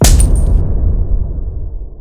chain-break2.mp3